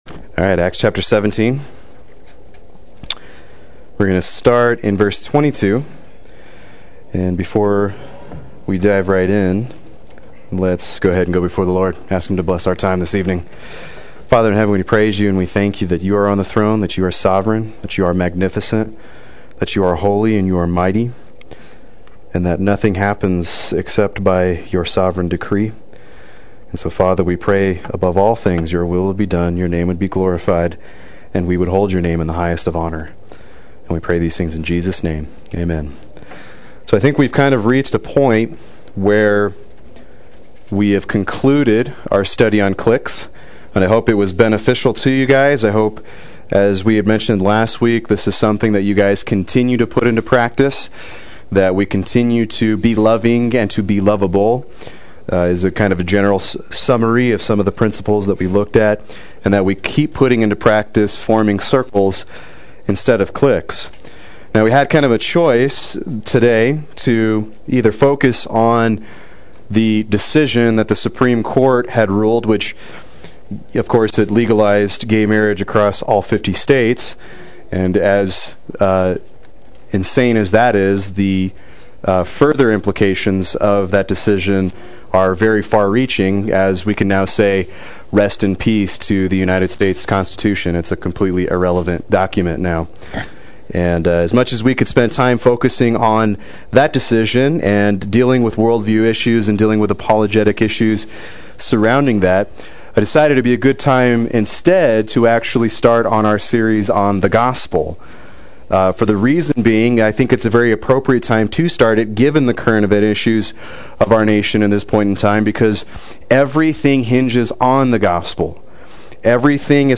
Proclaim Youth Ministry - 06/26/15
Play Sermon Get HCF Teaching Automatically.